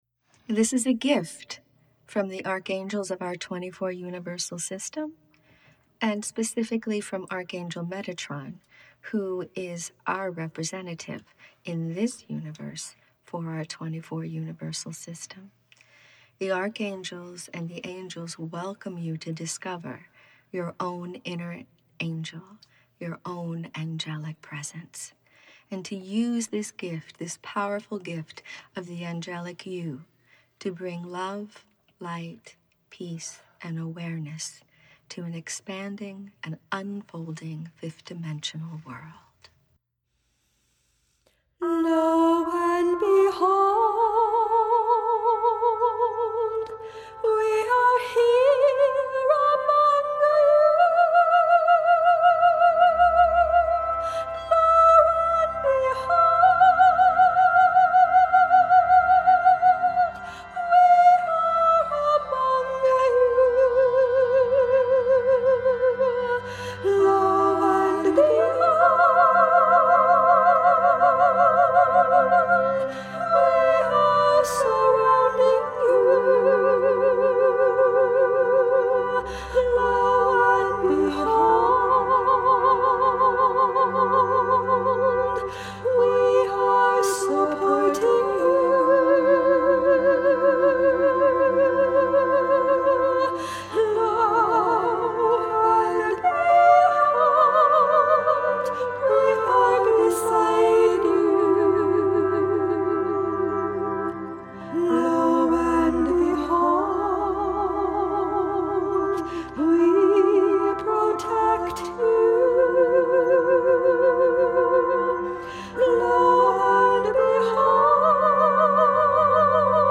Soundscapes